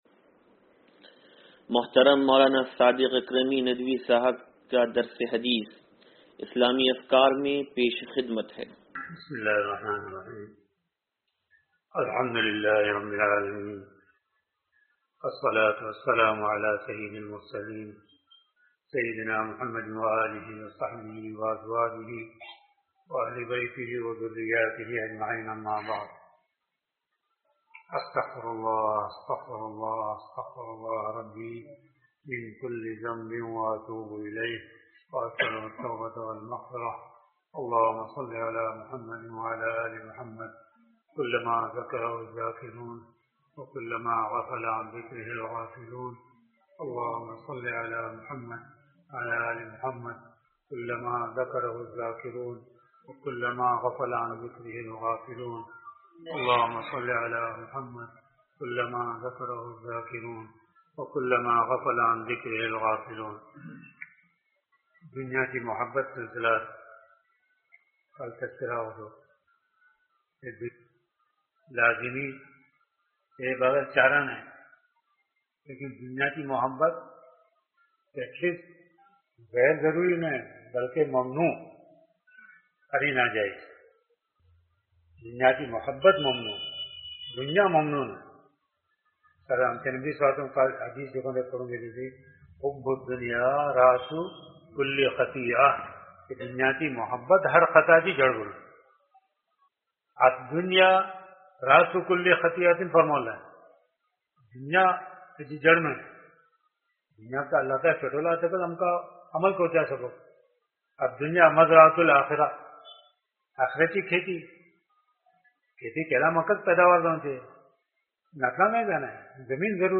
درس حدیث نمبر 0483